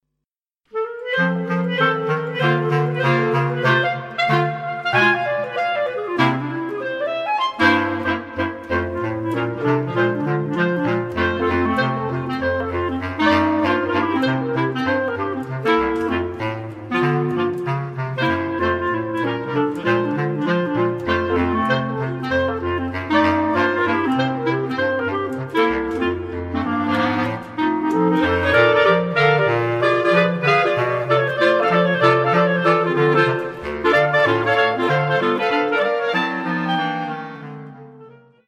En het derde fragment is een voorbeeld van lichte muziek.